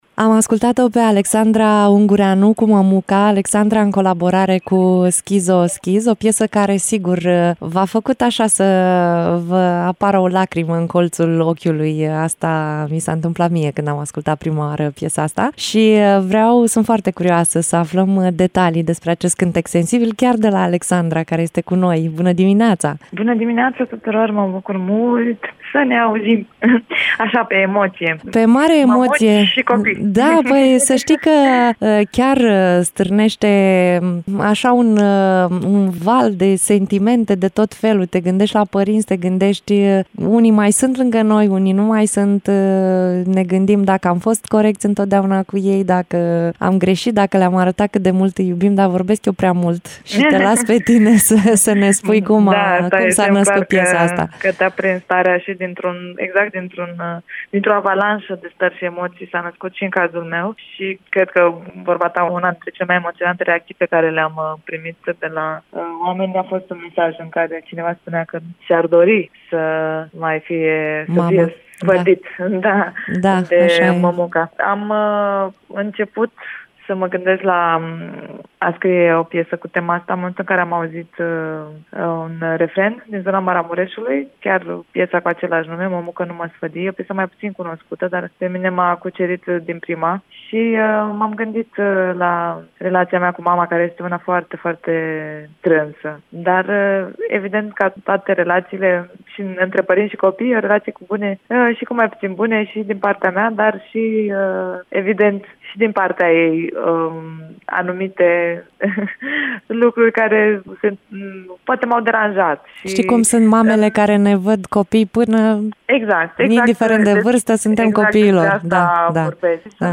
Alexandra Ungureanu, în direct la Radio Iaşi.
Interviu-Alexandra-Ungureanu.mp3